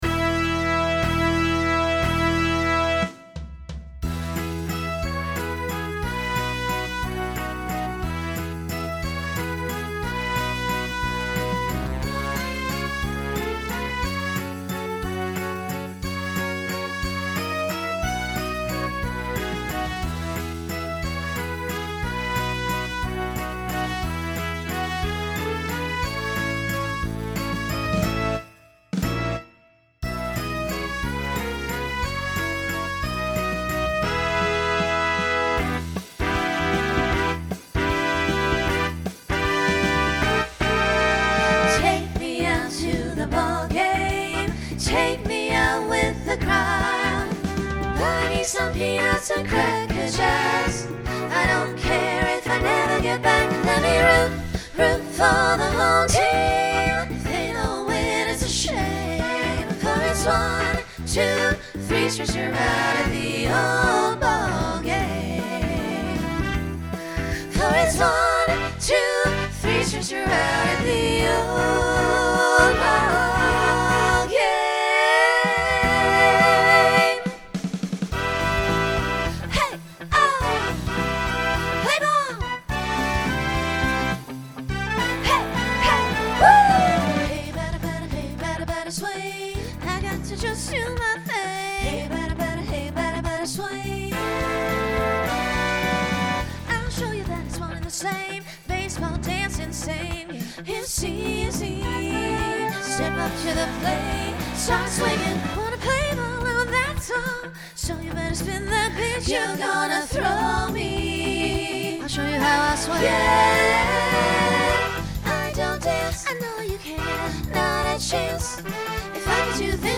Broadway/Film , Rock
Voicing SSA